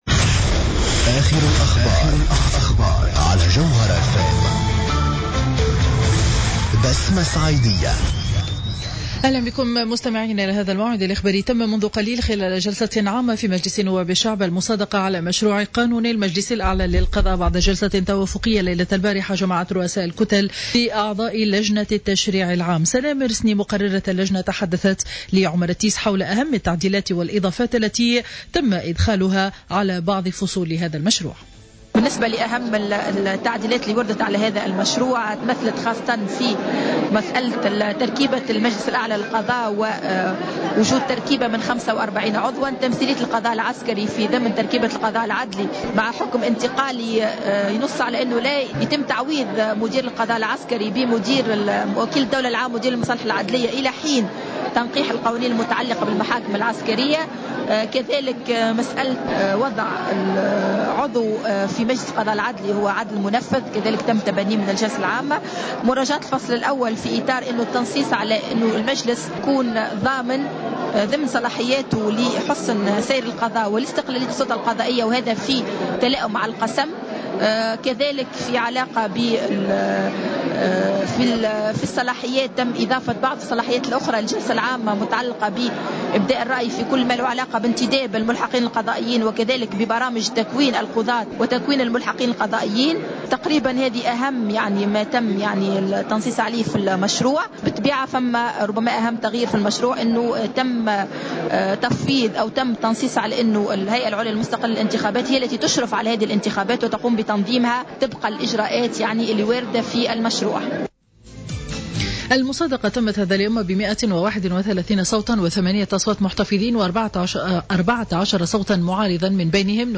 بوليتيكا مباشرة من الحمامات في إطار مواكبة اليوم الرابع لفعاليات المهرجان العربي للإذاعة و التلفزيون